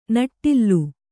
♪ naṭṭillu